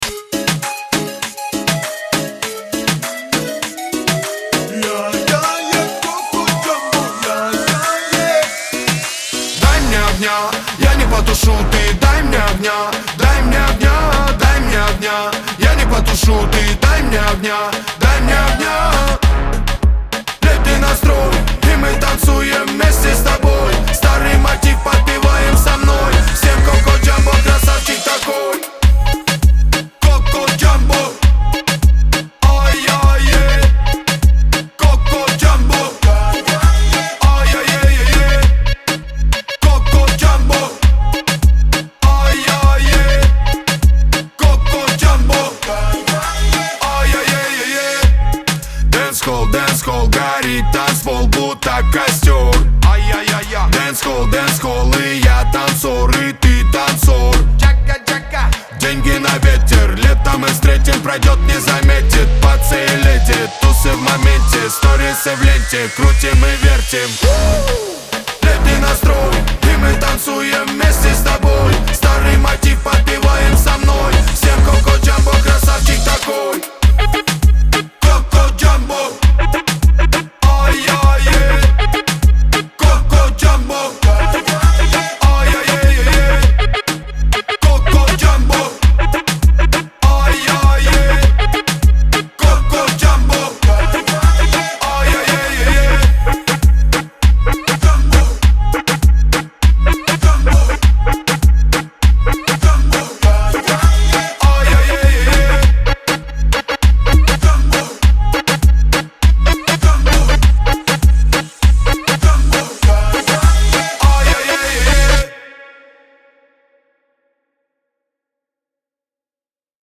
это энергичная и зажигательная песня в жанре евро-диско